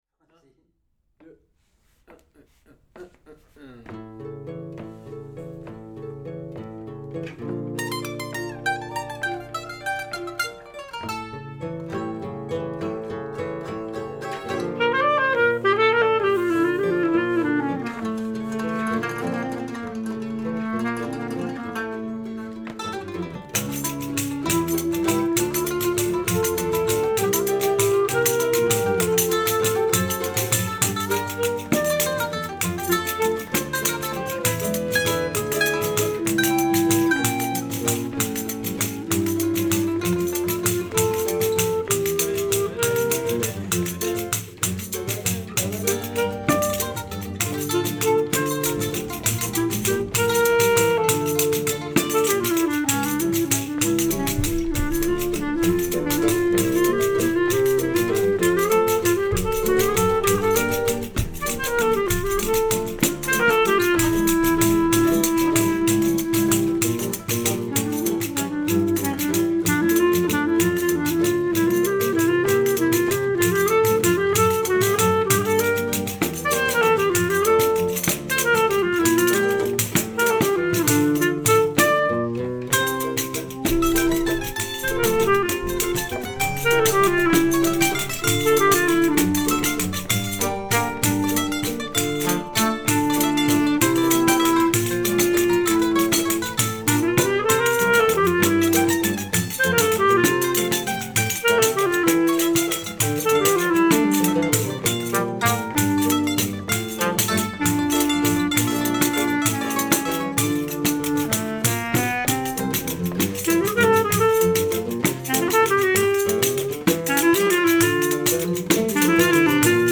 Rec atelier
Répétition